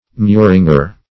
Muringer \Mu"rin*ger\